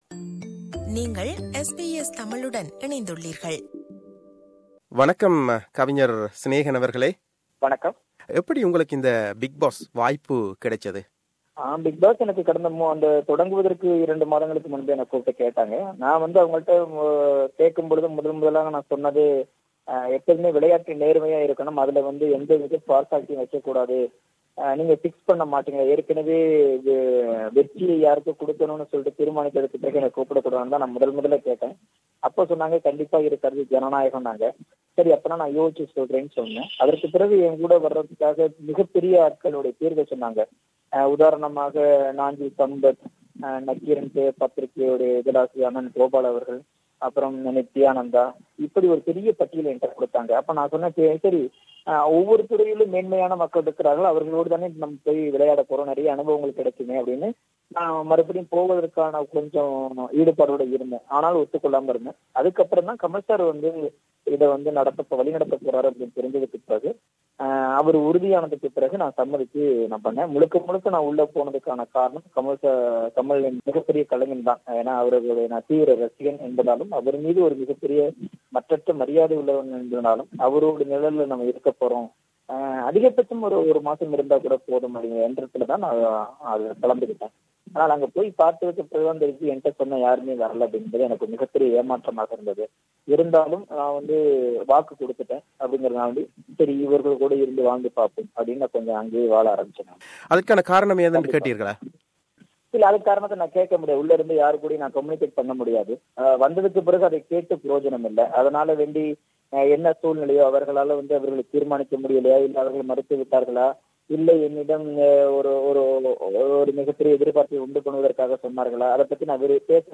BigBoss Snehan - Interview Part 1